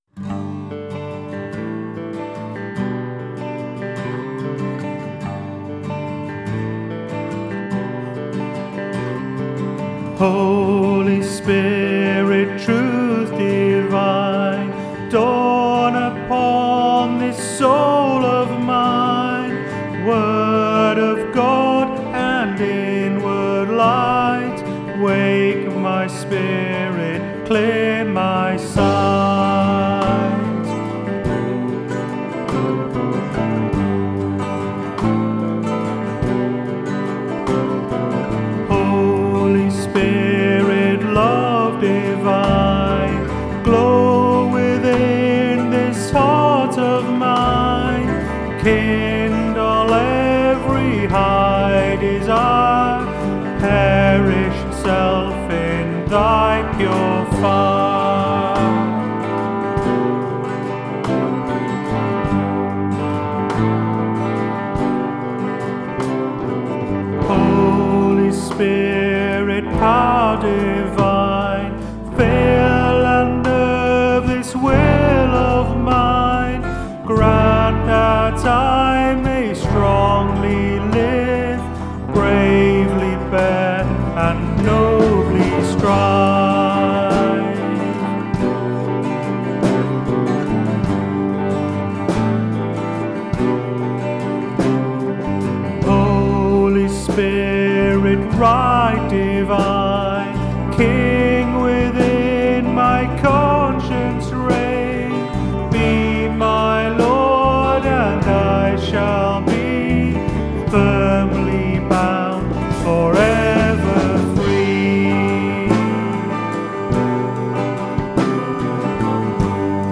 Modern arrangements of hymns from the 1933 edition of ‘The Methodist Hymn Book’.
These are all rough demos, don’t expect perfection!
I’ve taken more liberties with the melody on this one.